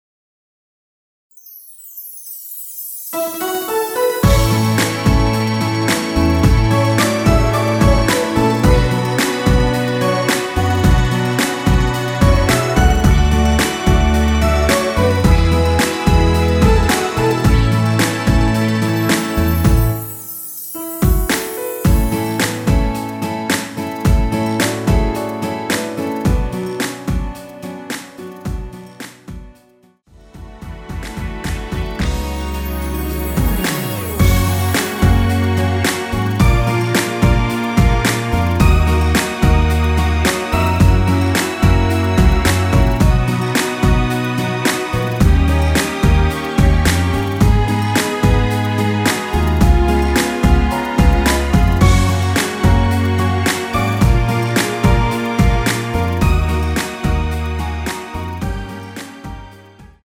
전주가 너무길어 시작 Solo 부분22초 정도 없이 제작 하였으며
엔딩부분이 페이드 아웃이라 엔딩을 만들어 놓았습니다.(미리듣기 참조)
앞부분30초, 뒷부분30초씩 편집해서 올려 드리고 있습니다.
중간에 음이 끈어지고 다시 나오는 이유는